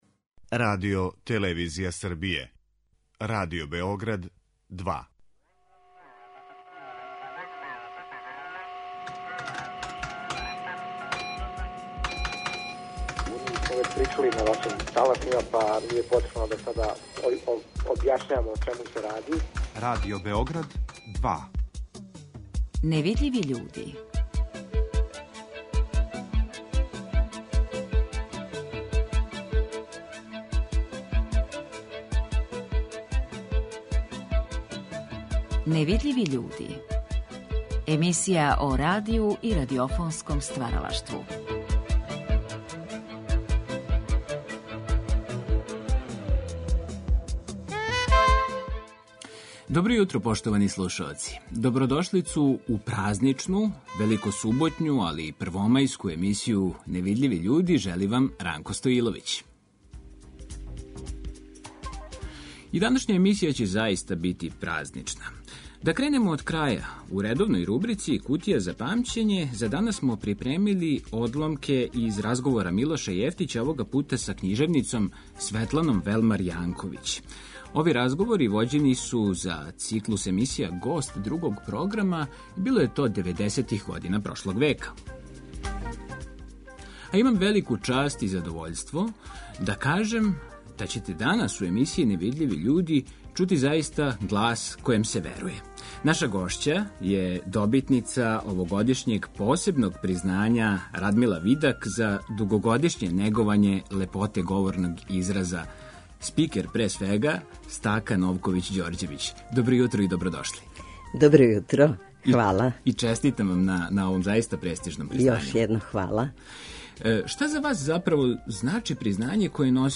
Ови разговори вођени су деведесетих година прошлог века у циклусу емисија Гост Другог програма.